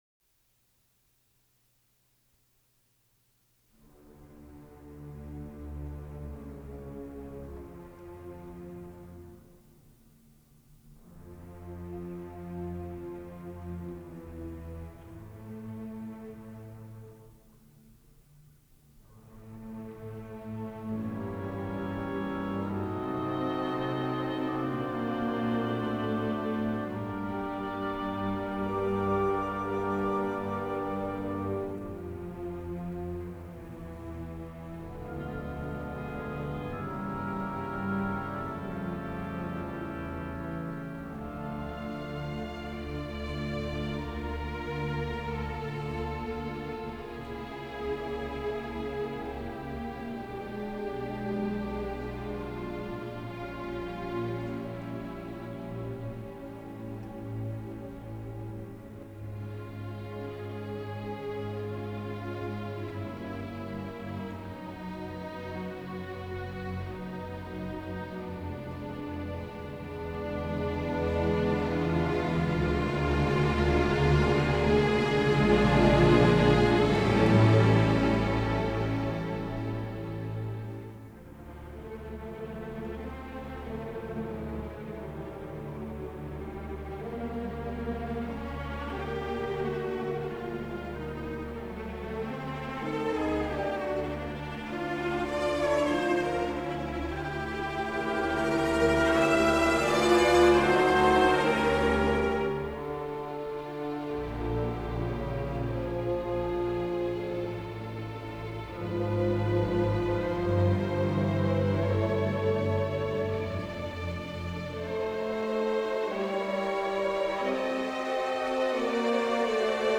Orchestra Hall, Chicago Engineer